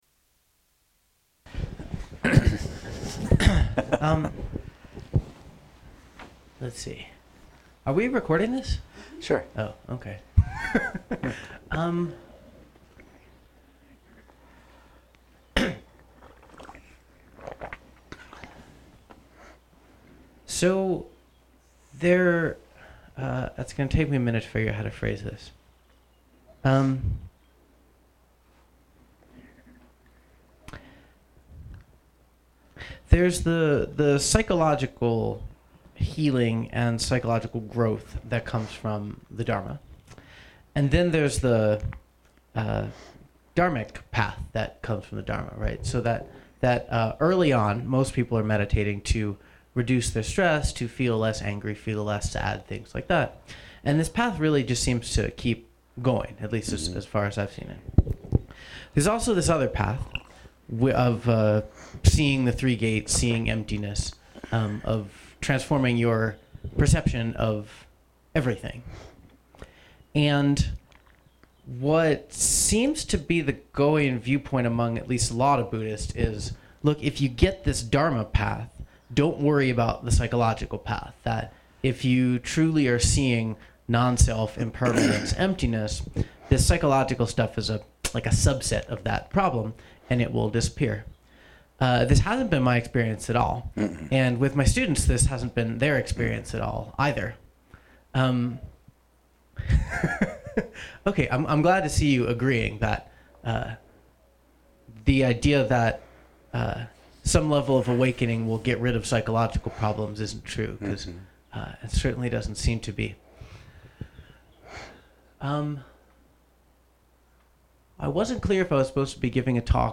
MP3 of the Dharma talk at the Stronghold